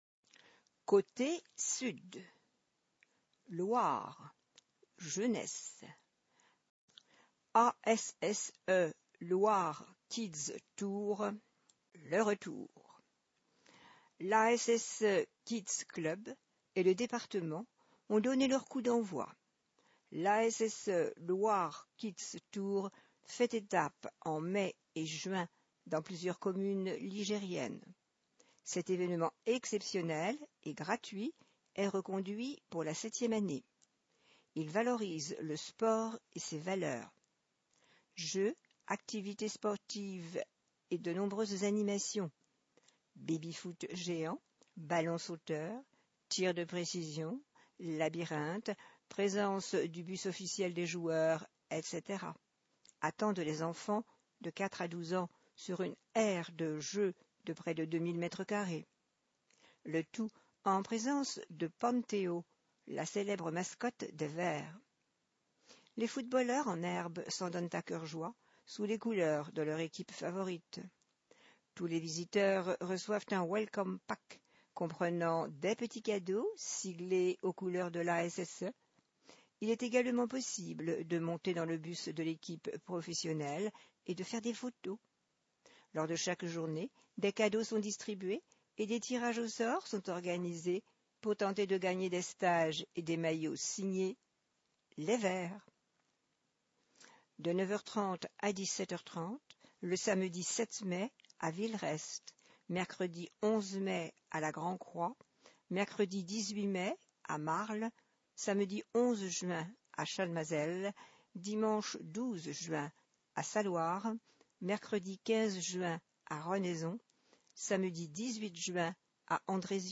Loire Magazine n°151 version sonore